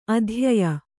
♪ adhyaya